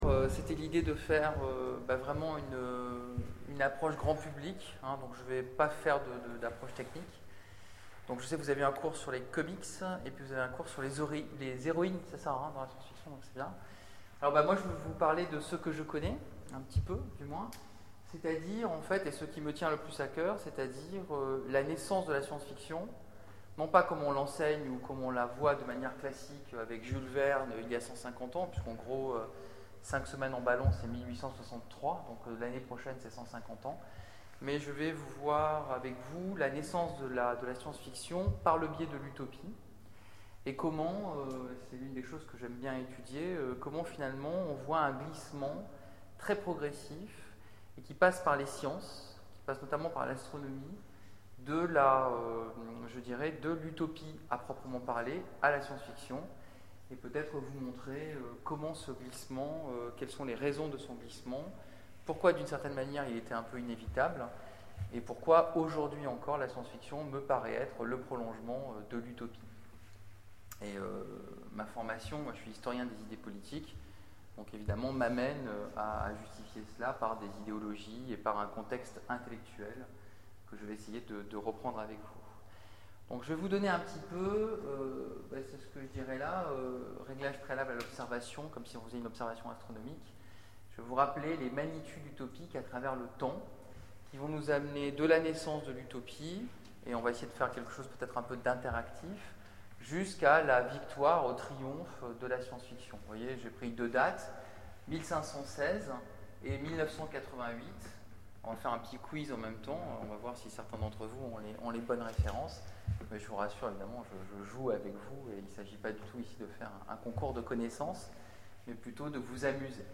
Utopiales 12 : Cours du soir Des utopies à la science-fiction
Attention, malheureusement nous n'avons que le début en audio...
Mots-clés Utopie Conférence Partager cet article